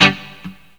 RIFFGTR 19-L.wav